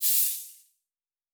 Sci-Fi Sounds
Air Hiss 2_02.wav